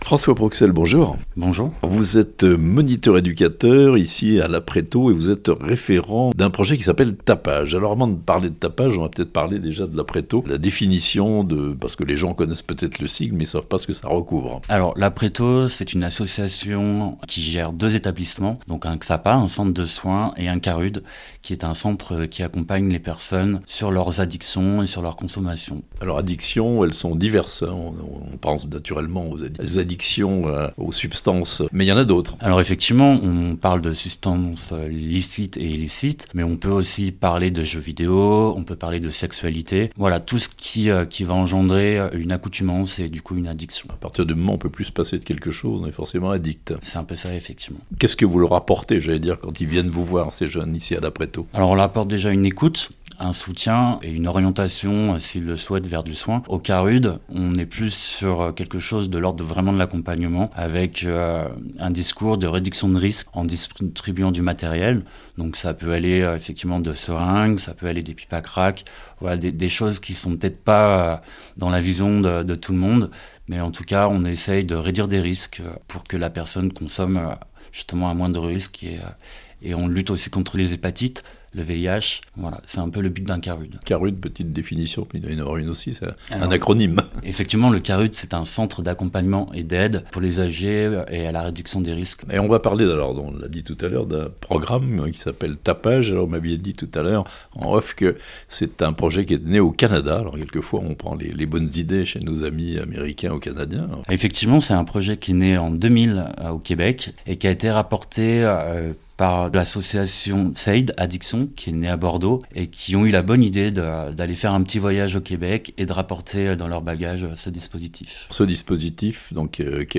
A Annemasse la lutte contre les addictions passe aussi par le programme TAPAJ (interview)